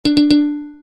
Звуки игрового автомата
Звук ошибки